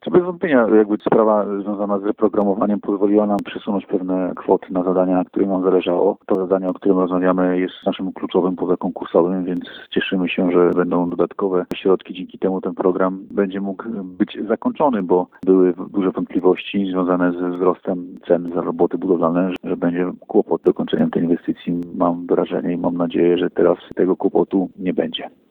Wicemarszałek Województwa Warmińsko-Mazurskiego Marcin Kuchciński mówi, że to powinno rozwiązać problem, który powstał w wyniku wzrostu cen prac budowlanych.